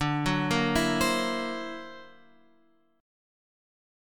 D+9 chord {10 9 8 9 x 8} chord